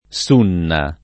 sunna [ S2 nna ; ar. S2 nna ] s. f.